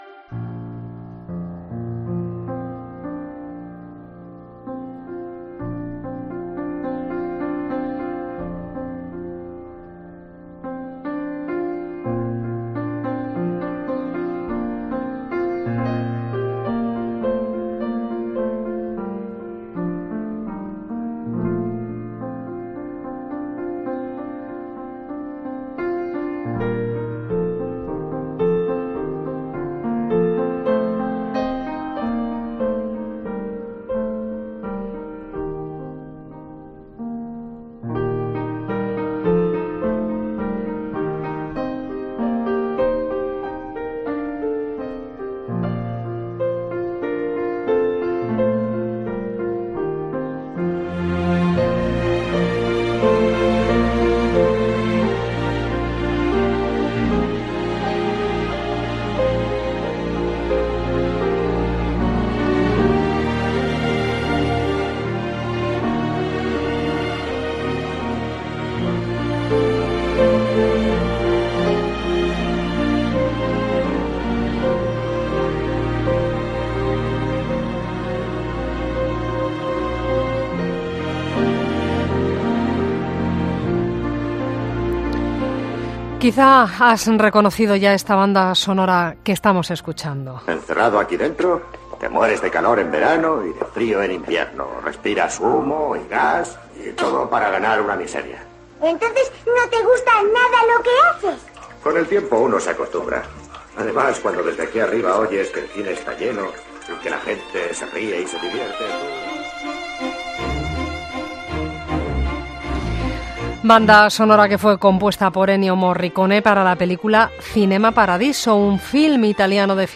Mariano Barroso, es el presidente de la Academia de Cine y nos cuenta en la linterna la situación actual del cine español.